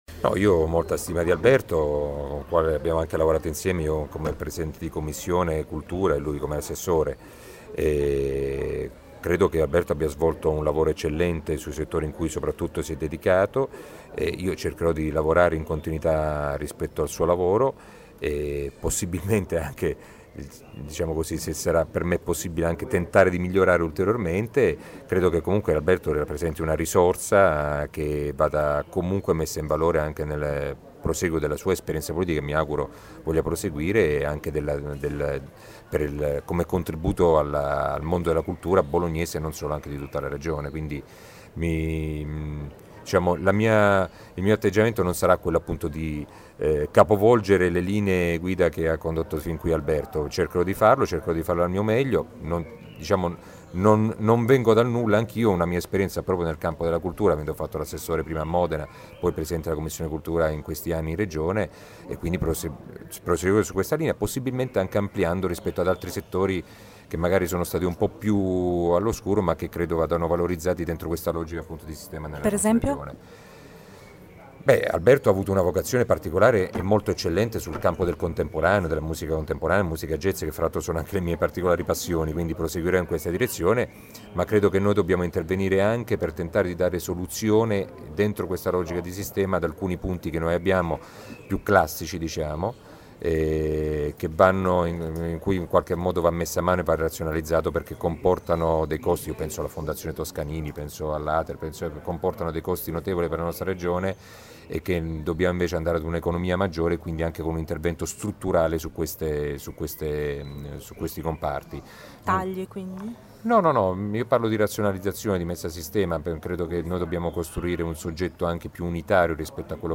Ascolta il neo assessore